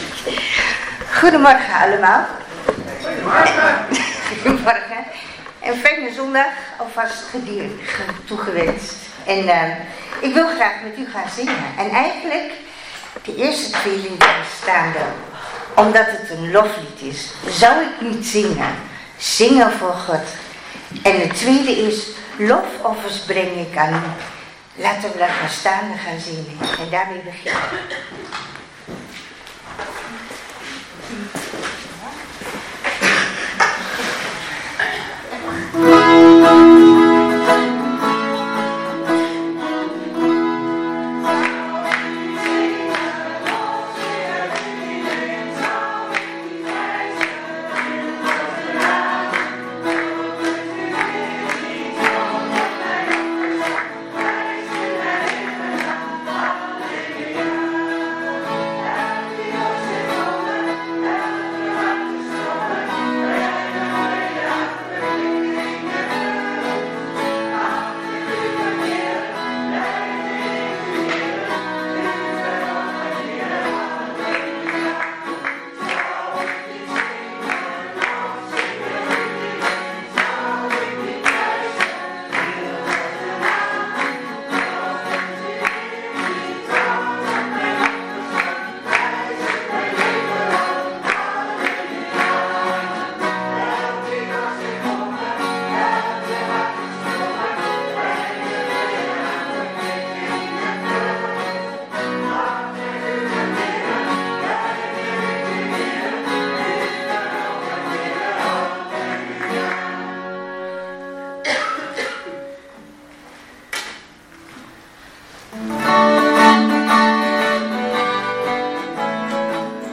7 december 2025 dienst - Volle Evangelie Gemeente Enschede
Preek